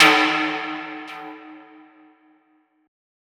Perc  (12).wav